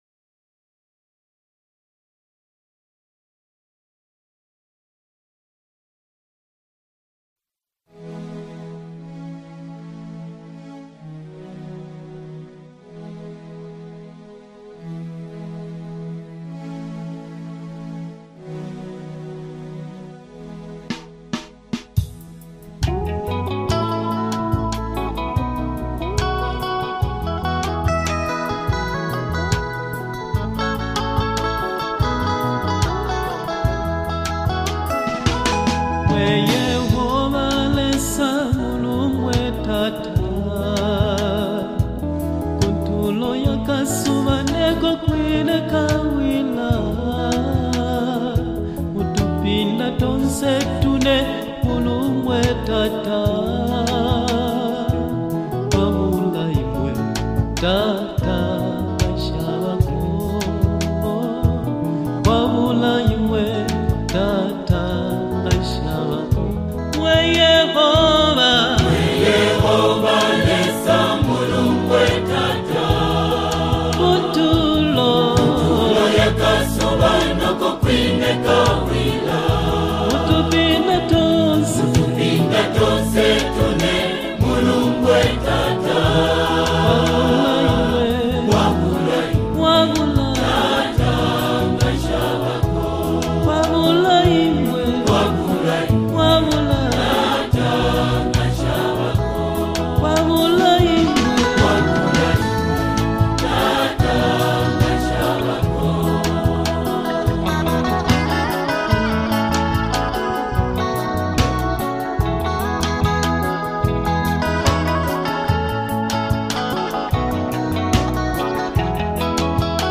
Latest Zambian Worship Song